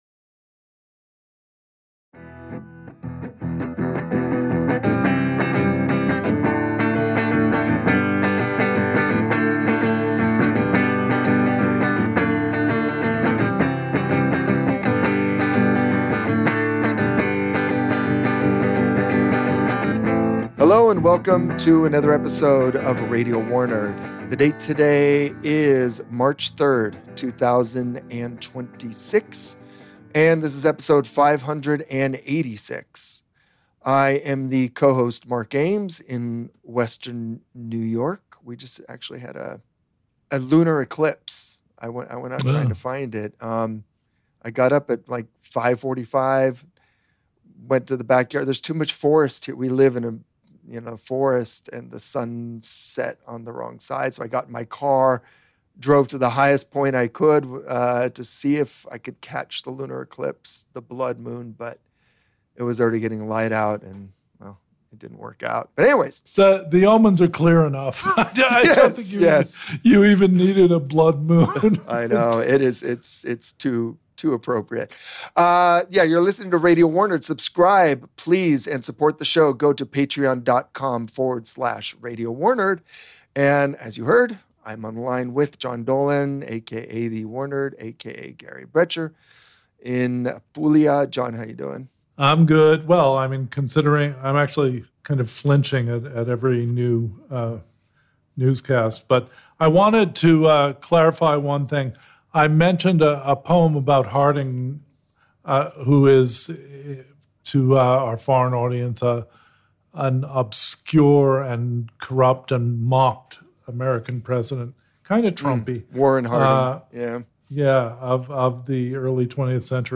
Guest: Robert Pape Recorded: March 3, 2026 We talk to air war expert Robert Pape about the strategic failures of air wars in the age of precision weapons, the escalation trap the failure presents, and how it relates to the US-Israeli war on Iran.